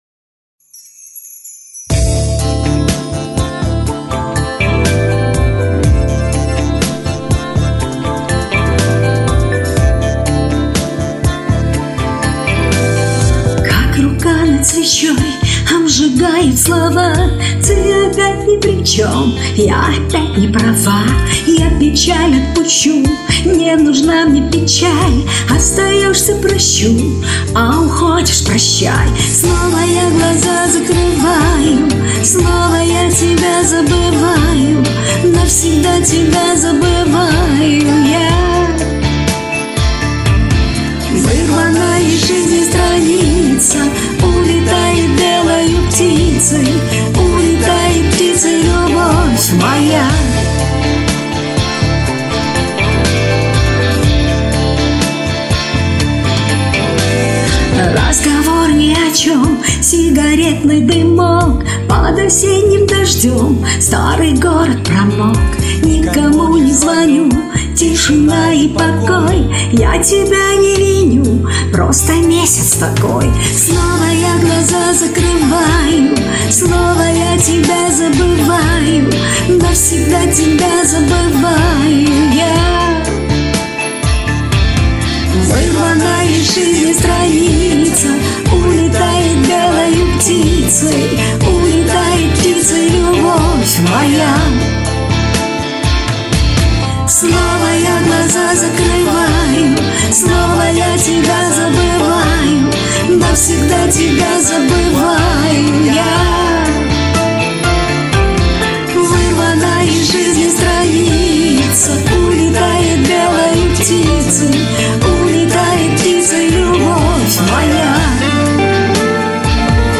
слишком быстрый темп - не хватило места для чуйвств))
С вокалом у всех всё в порядке.